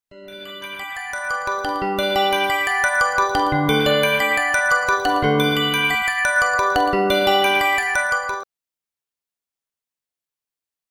Kategorie SMS